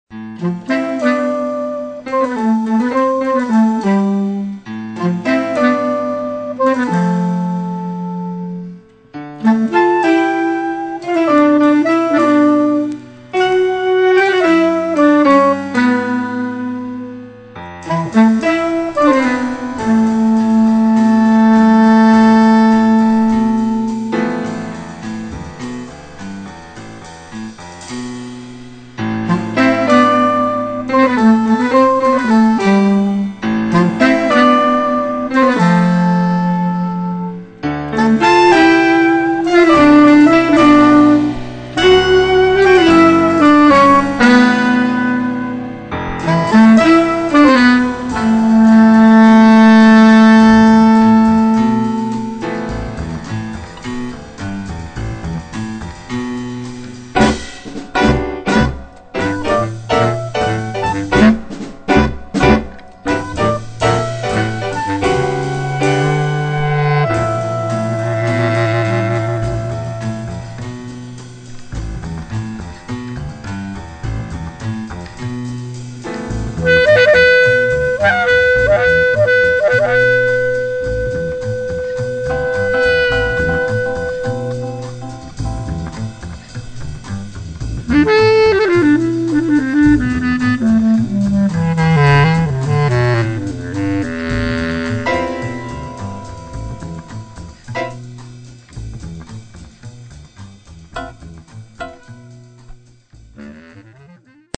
clarinette basse, harmonica
guitare acoustique
guitare électrique
contrebasse
batterie